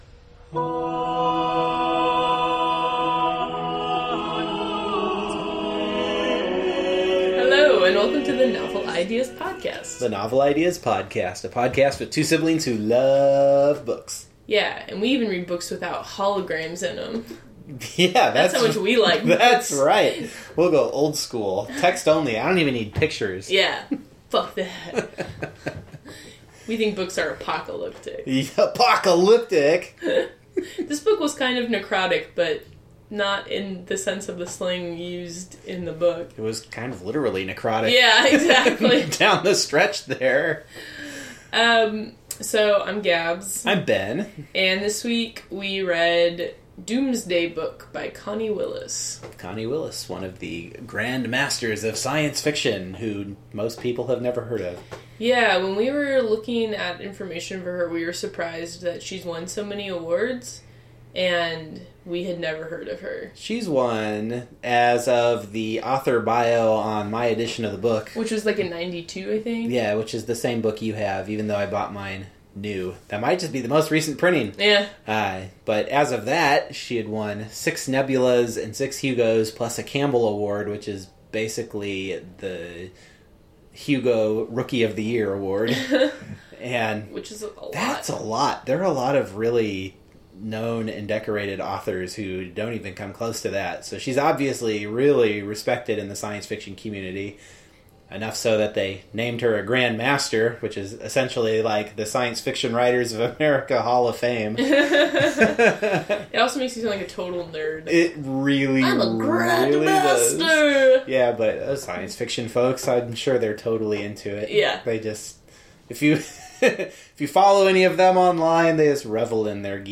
The music bump is “Messe de Notre Dame” by Guillaume de Machaut, a contemporary of the novel’s 14th century time line who also happens to share the name of an often referenced character who never actually shows up in the book.